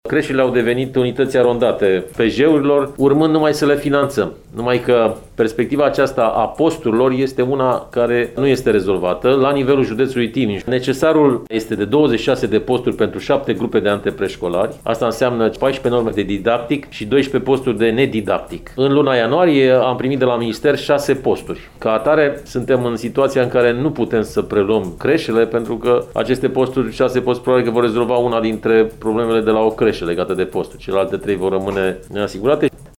Inspectorul şcolar general, Marin Popescu, spune că a avut o discuţie pe această temă, cu Comisia de Dialog Social.